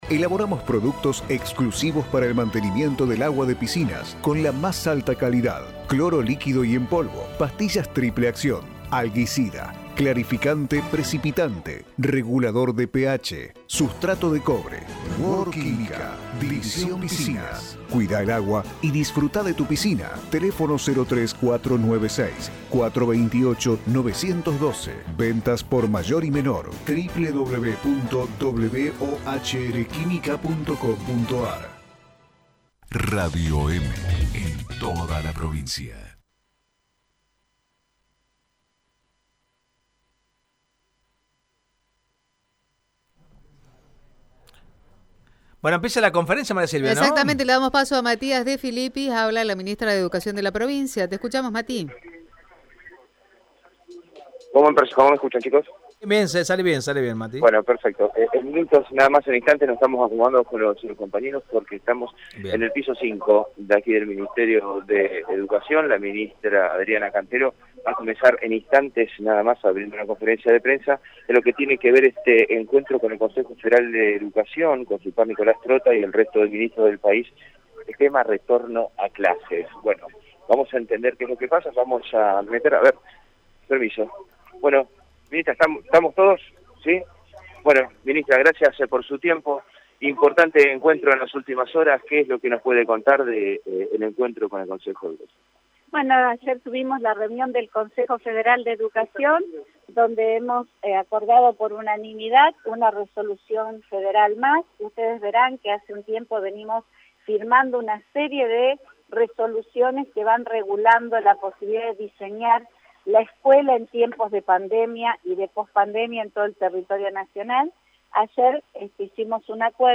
Así lo confirmó la Ministra de Educación, Adriana Cantero en conferencia de prensa.